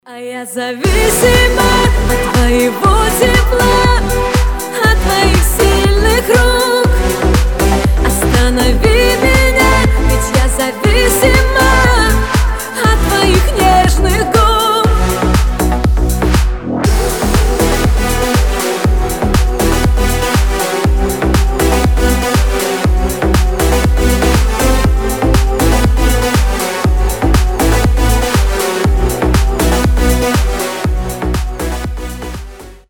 • Качество: 320, Stereo
красивый женский голос